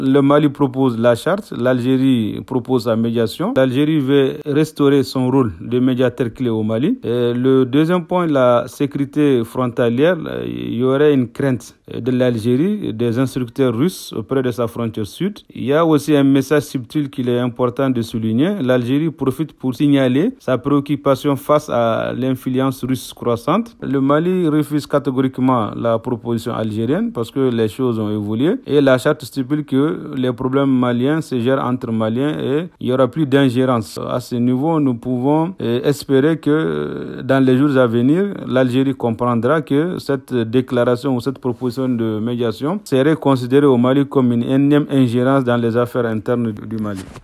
02-REACTION-ANALYSTE-MEDIATION-ALGERIE-FR.mp3